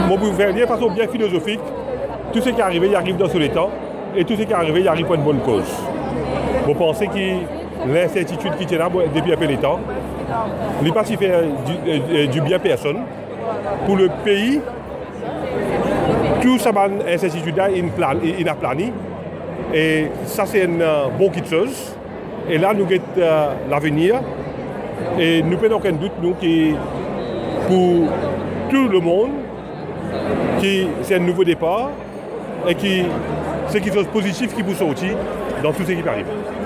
Sur un ton philosophe, il décrète que tout ce qui arrive, arrive pour une bonne cause. Plus concrètement, il salue le fait que l'annonce de sir Anerood Jugnauth met fin à l'incertitude qui prévalait jusqu'ici et qui ne faisait de bien à personne.